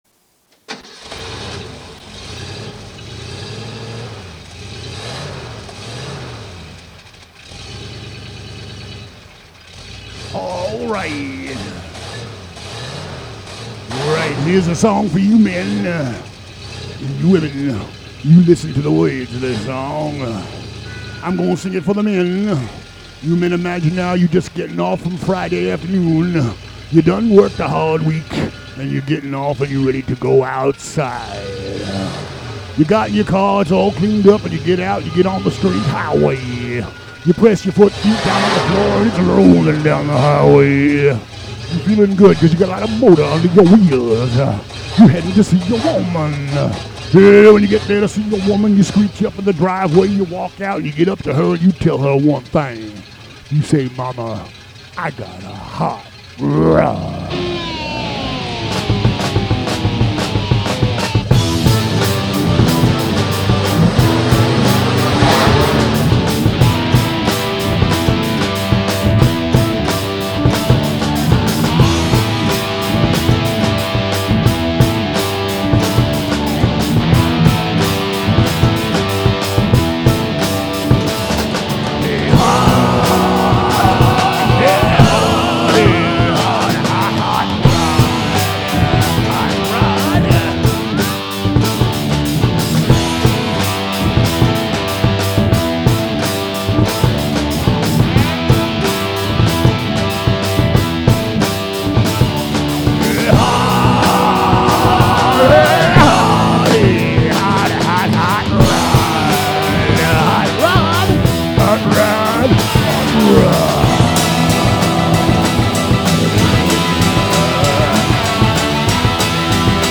vocals
keyboards
guitars
harmonica
drums